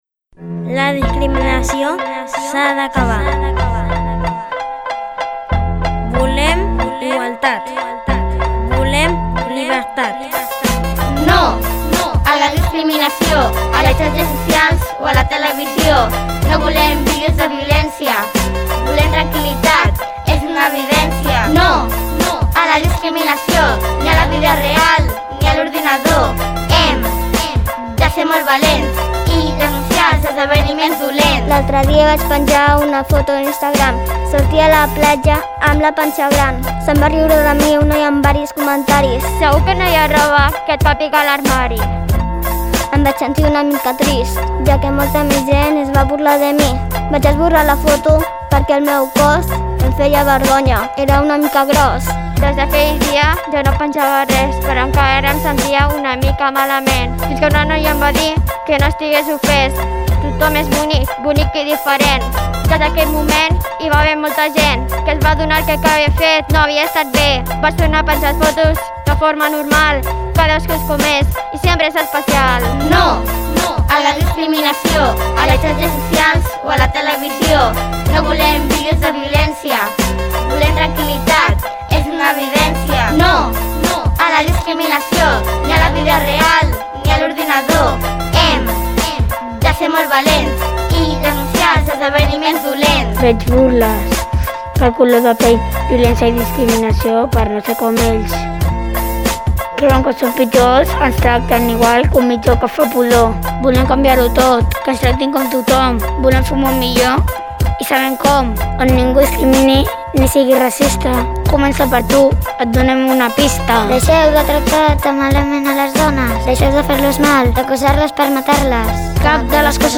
Durant aquestes sessions, els infants de 4t han pogut pensar i crear la lletra, per interpretar dues cançons en català amb una base rítmica de rap.